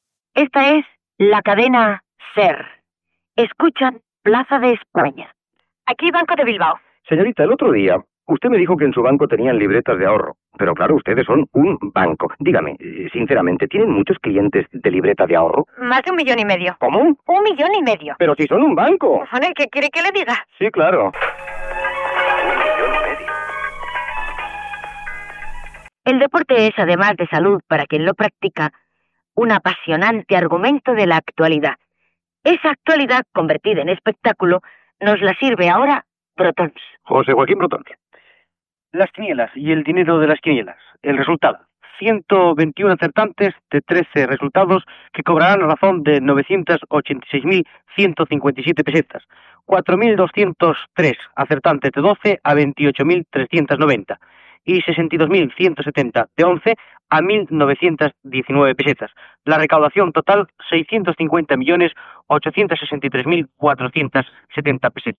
Identificació de la cadena i del programa, publicitat, resultat dels premis de la travessa de futbol
Informatiu